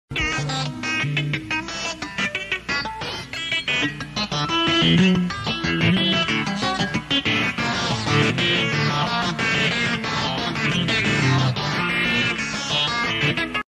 ExperimentalGuitar.mp3